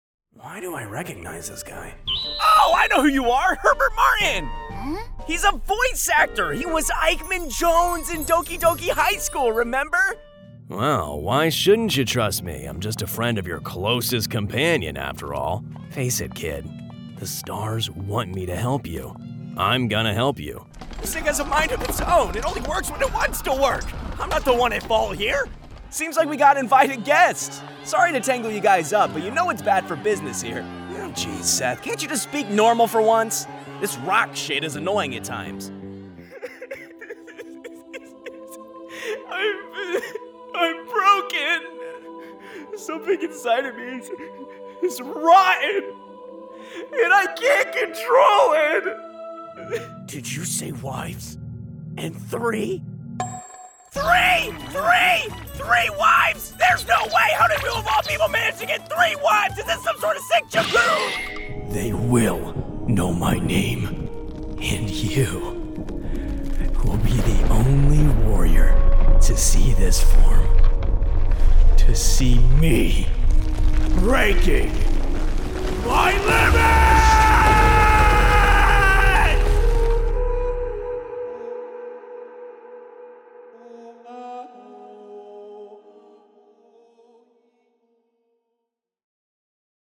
Professional Character Demo Reel
All North-American Accents, Aristocratic British, General European